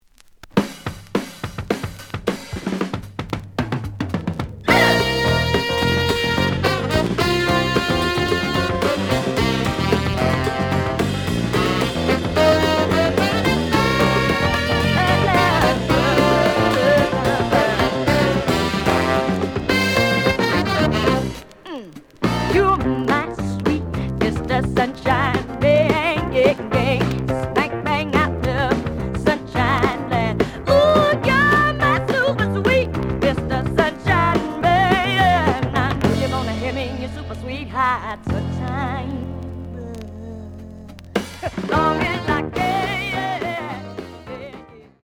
(Mono)
試聴は実際のレコードから録音しています。
●Genre: Soul, 70's Soul